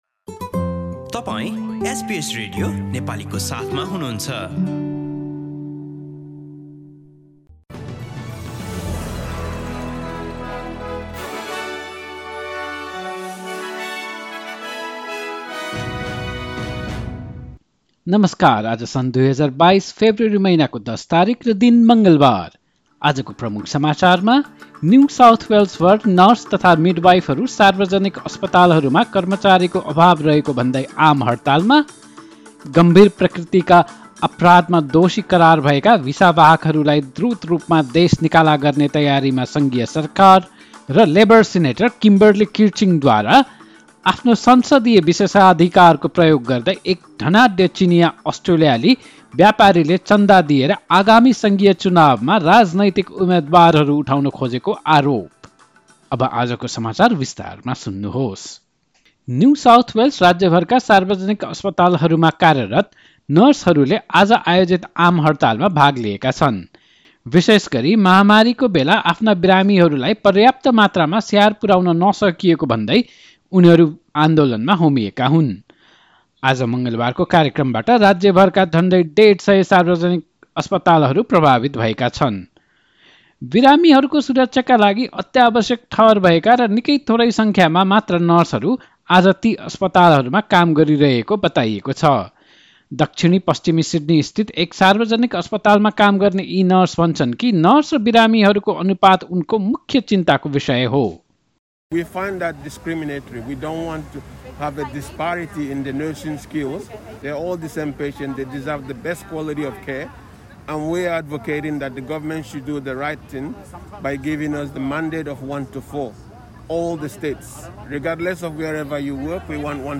एसबीएस नेपाली अस्ट्रेलिया समाचार: मङ्गलवार १५ फेब्रुअरी २०२२